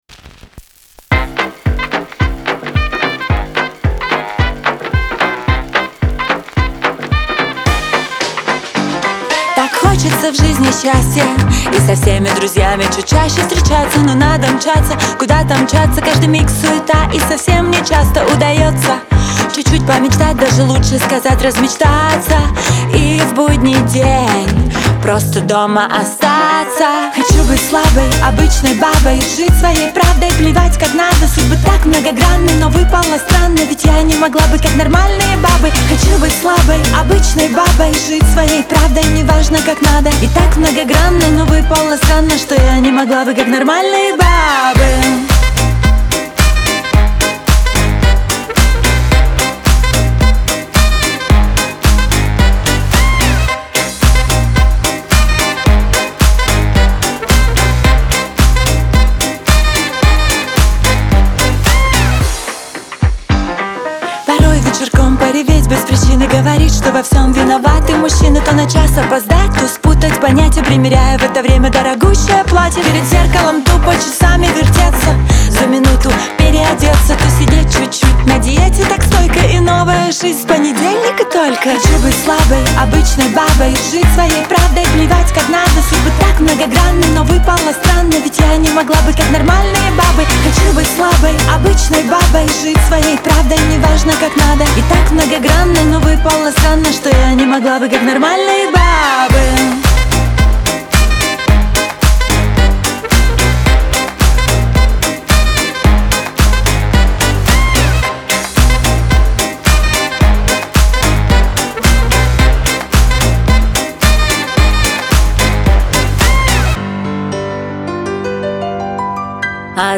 исполняет композицию с яркой эмоциональной окраской